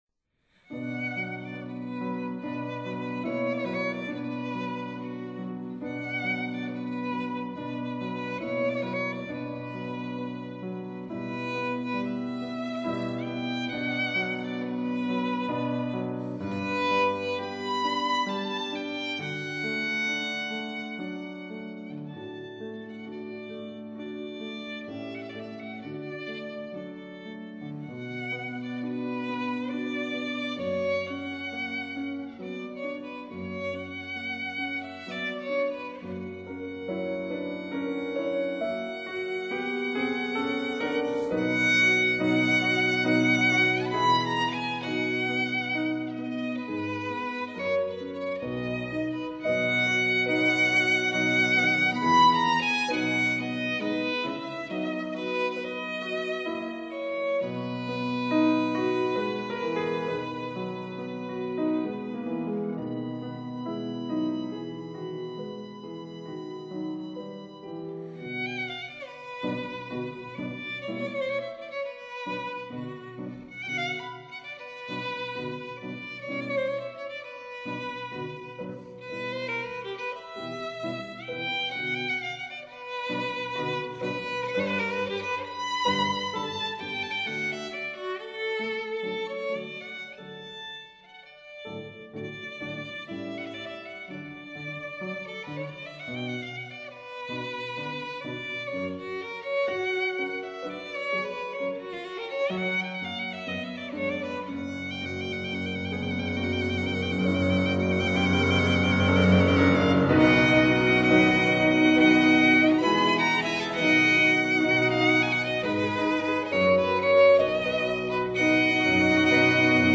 violin
piano